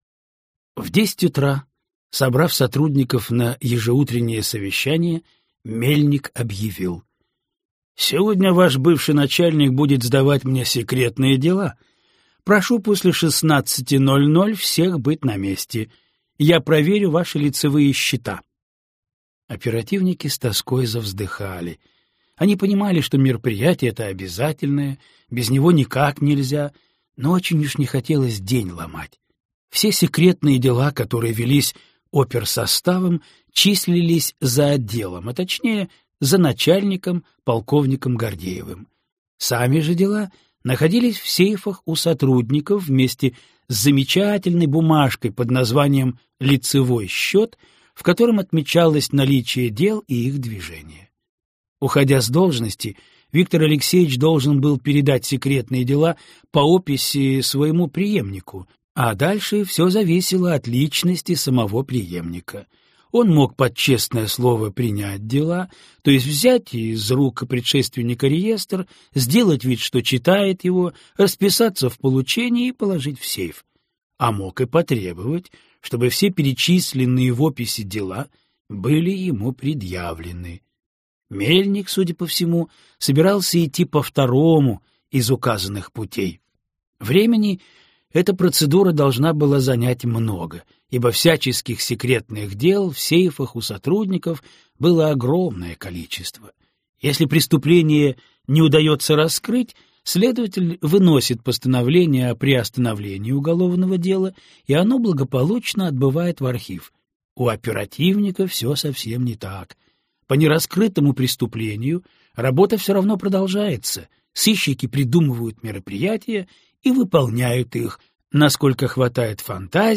Аудиокнига Мужские игры | Библиотека аудиокниг